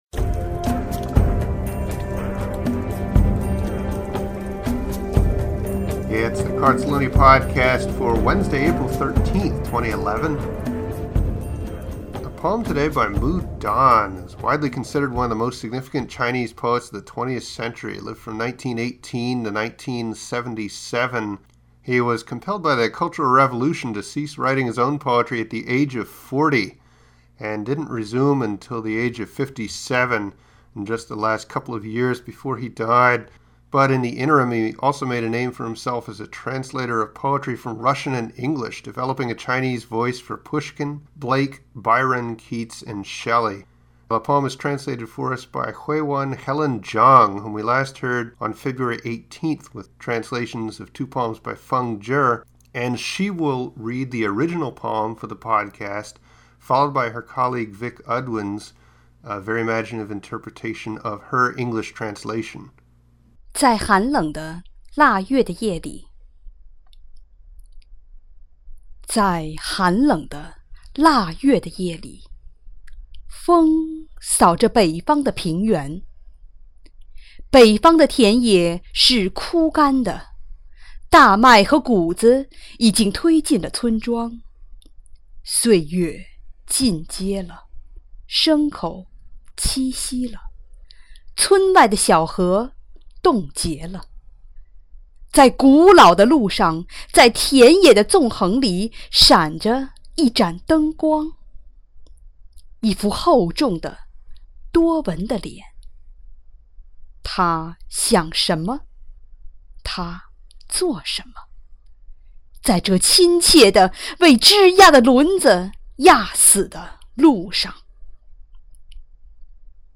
Beautiful reading